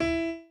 b_pianochord_v100l4o5e.ogg